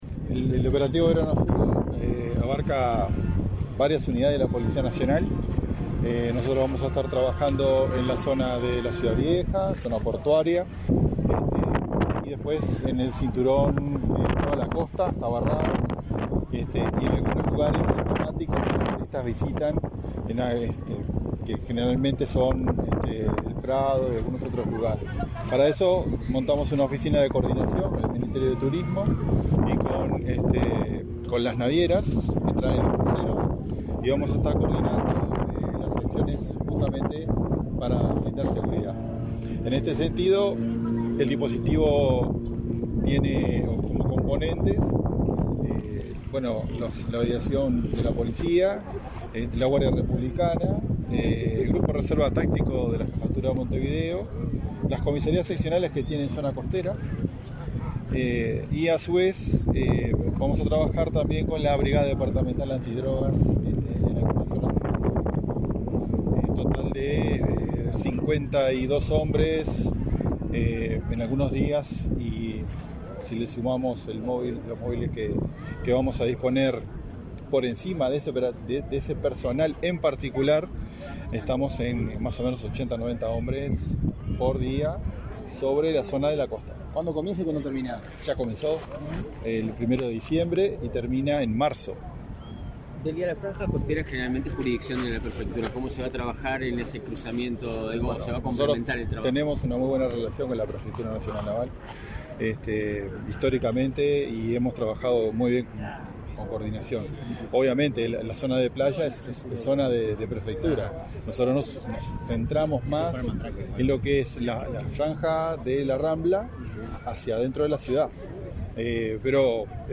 Declaraciones a la prensa del jefe de Policía de Montevideo, Mario D´Elía
Declaraciones a la prensa del jefe de Policía de Montevideo, Mario D´Elía 22/12/2022 Compartir Facebook X Copiar enlace WhatsApp LinkedIn El Ministerio del Interior presentó, este 21 de diciembre, el operativo Verano Azul, que reforzará la seguridad ante el incremento de turistas durante la temporada de verano. Tras el evento, el jefe de Policía de Montevideo, Mario D´Elia, realizó declaraciones a la prensa.